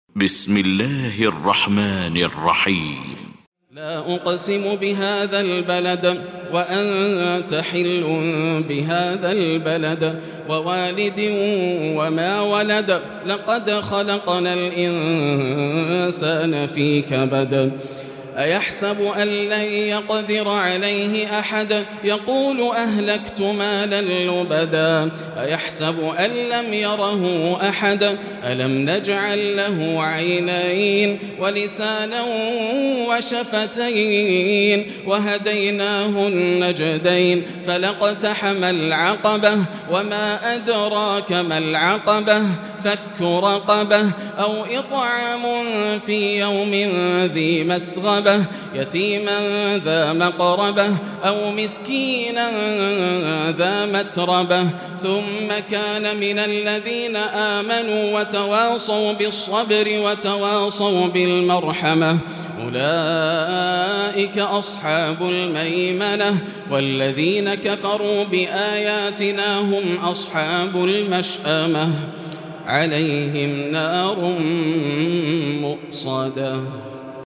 المكان: المسجد الحرام الشيخ: فضيلة الشيخ ياسر الدوسري فضيلة الشيخ ياسر الدوسري البلد